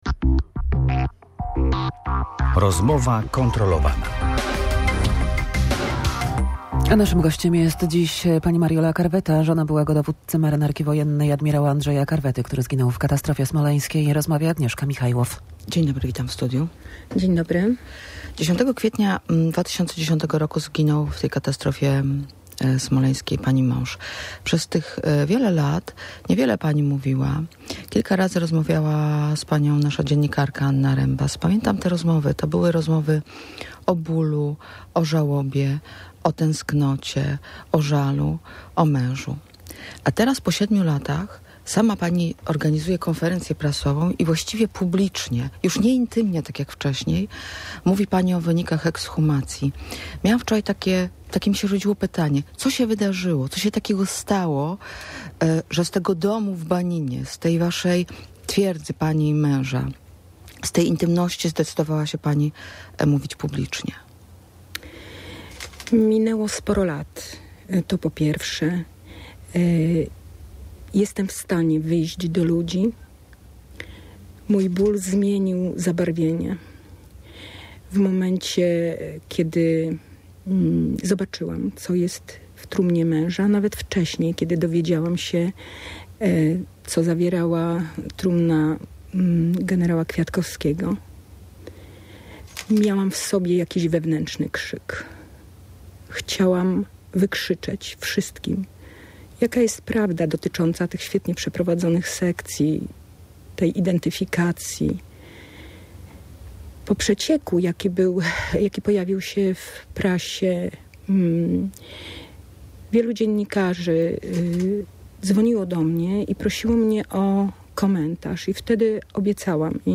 To było po prostu dla mnie nieprawdopodobne” [ROZMOWA]